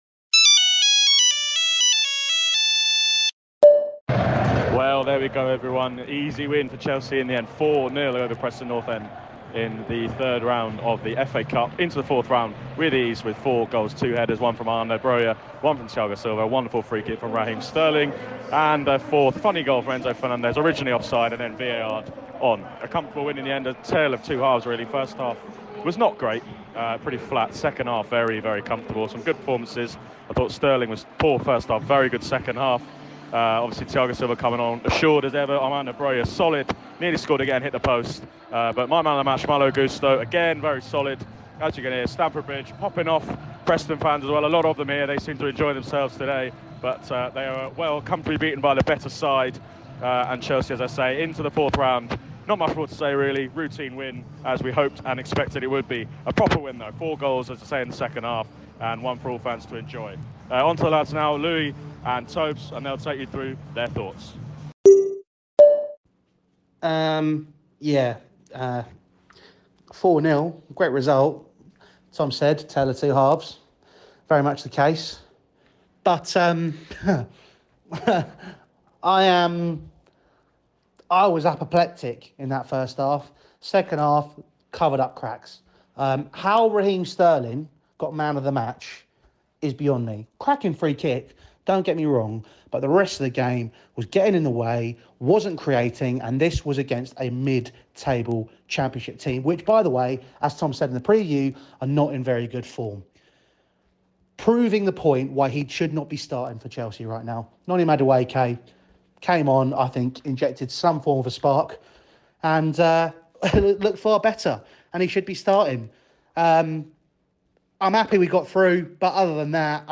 | Chelsea 4-0 Preston North End Voicenote Review